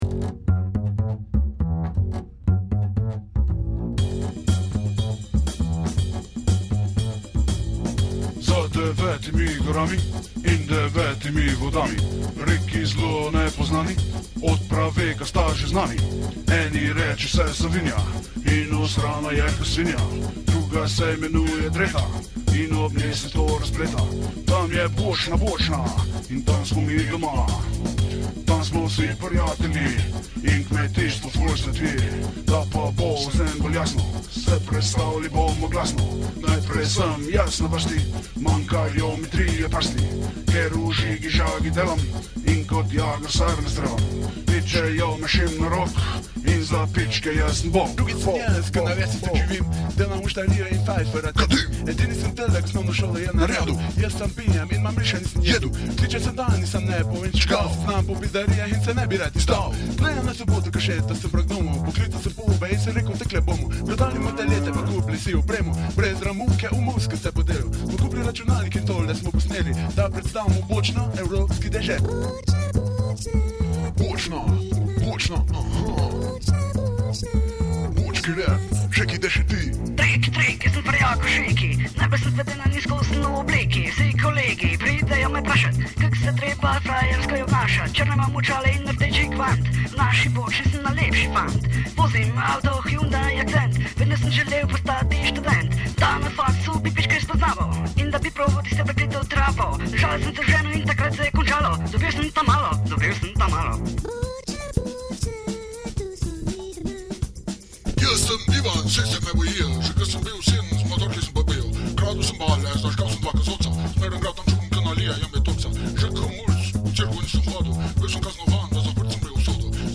bonus: ah ja, seveda, soundtrack, pojma nimam kako sem lahko pozabil na to ekstremno rariteto in izjemni eksampel slovenskega ruralnega rapa … bochna ryapp u hiši!